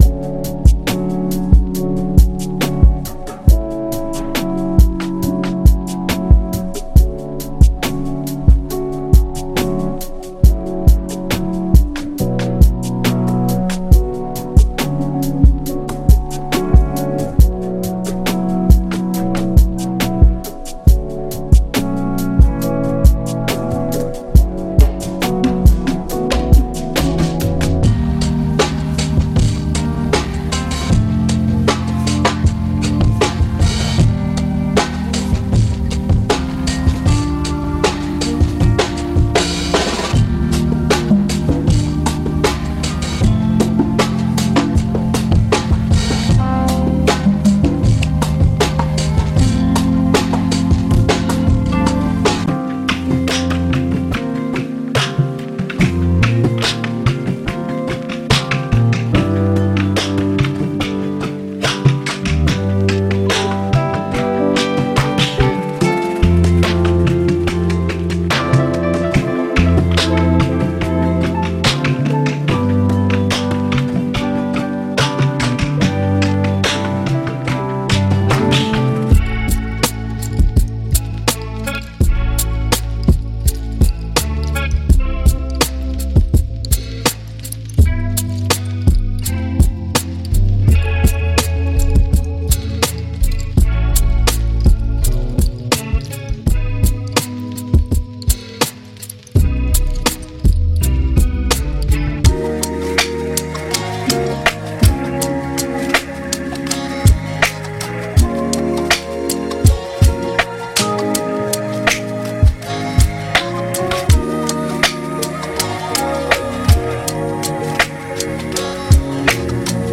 所有这些免版税的作品都必将帮助您创作受20世纪70年代灵魂和爵士乐启发的音乐。
•9 乙烯基噼啪声